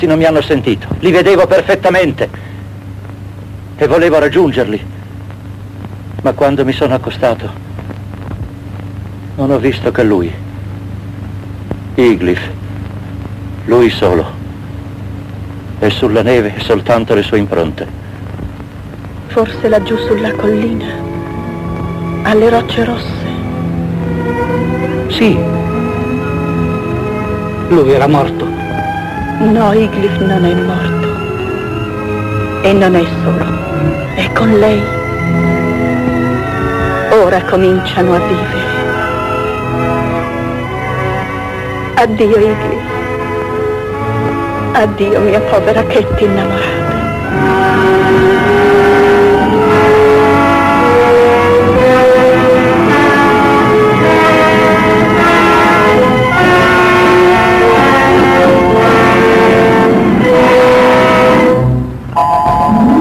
nel film "La voce nella tempesta", in cui doppia Donald Crisp.